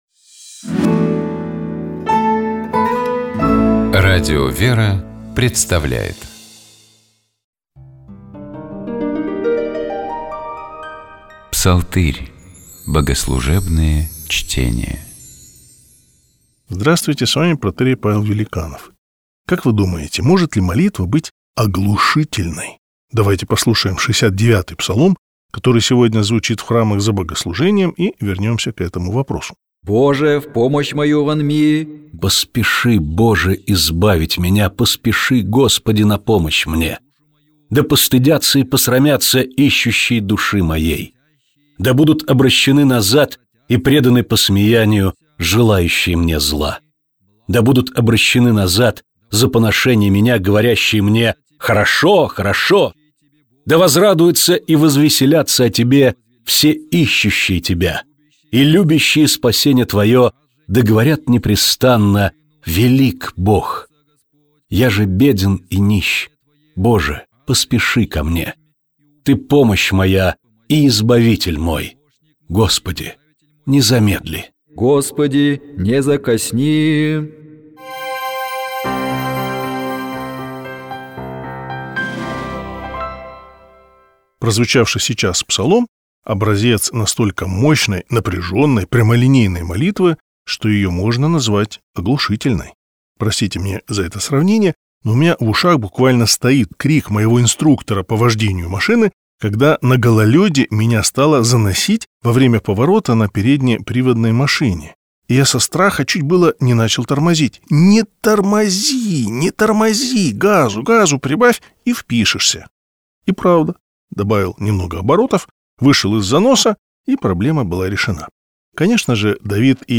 Например, тропарь, то есть гимн, посвящённый празднику. Давайте поразмышляем над текстом песнопения и послушаем его в исполнении сестёр храма Табынской иконы Богородицы Орской епархии.